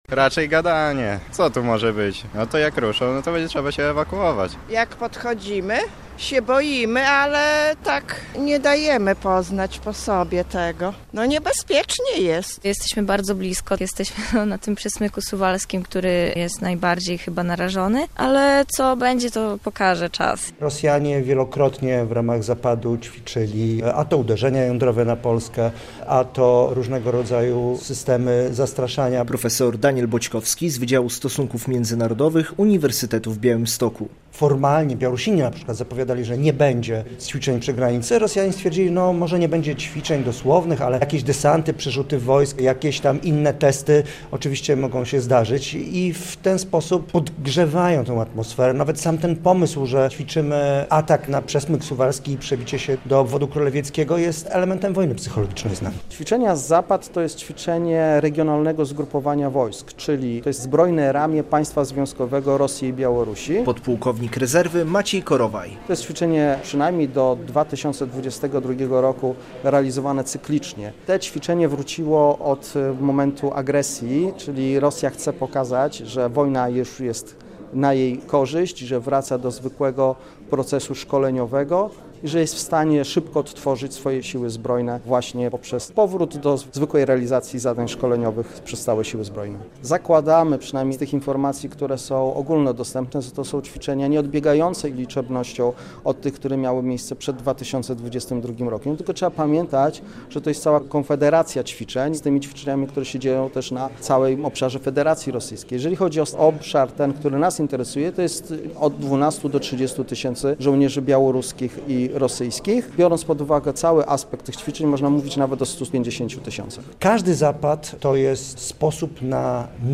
Manewrom rosyjsko-białoruskim Zapad 2025 towarzyszy kampania dezinformacyjna i sianie chaosu w Polsce i nie tylko. O tym mówili wykładowcy Wydziału Stosunków Międzynarodowych Uniwersytetu w Białymstoku i zaproszeni goście podczas seminarium "Bezpieczeństwo wschodniej granicy Unii Europejskiej".